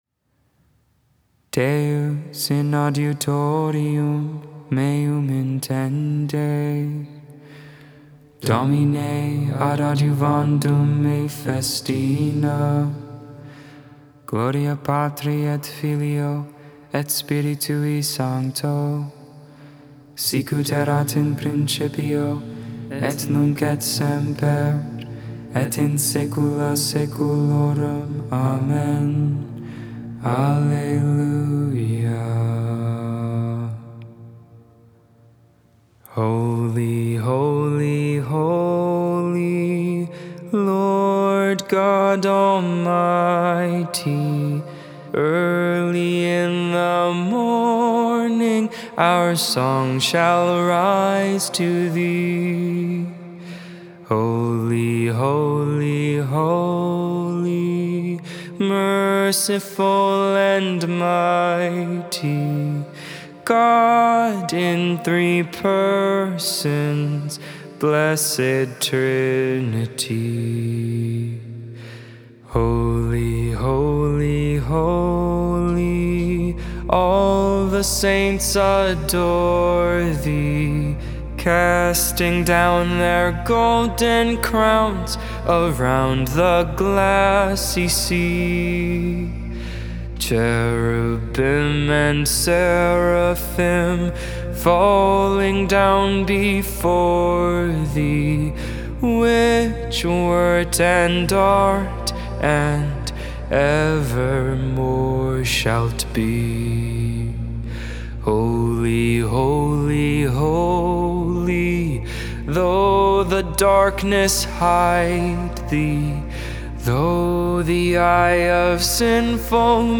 Gregorian tone 4